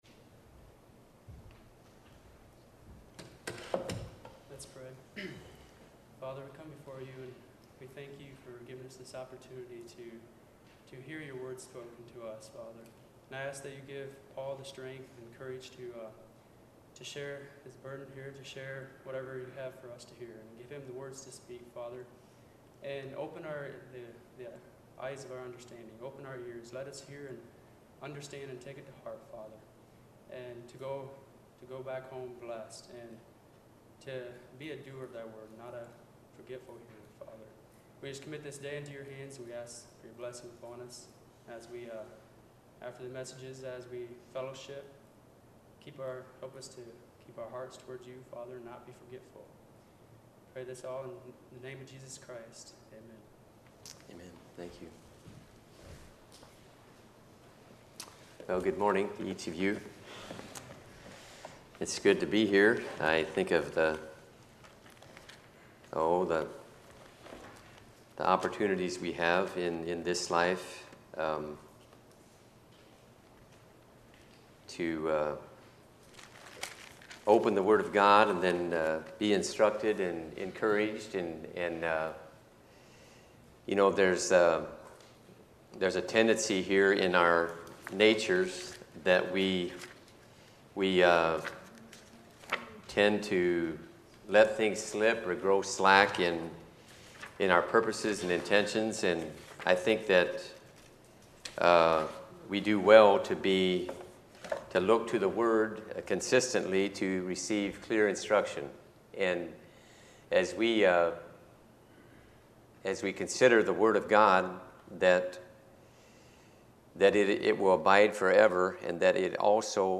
Youth Meeting Messages